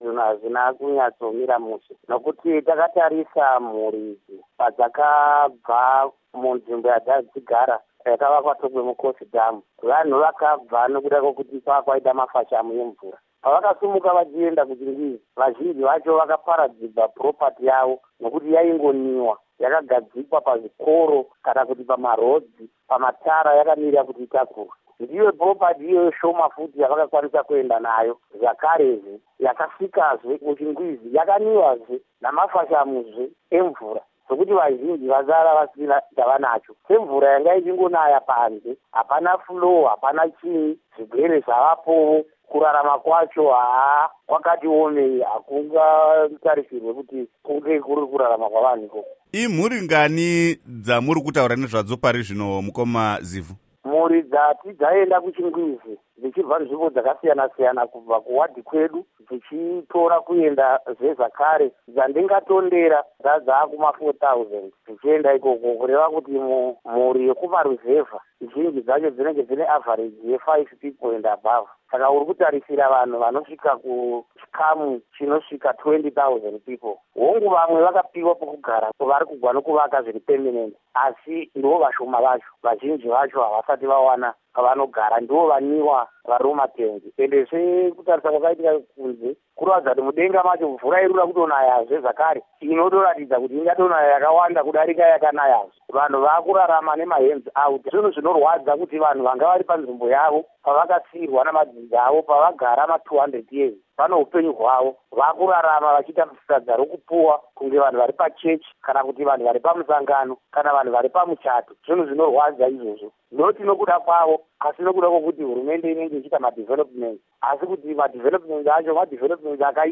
Hurukuro naVaKiller Zivhu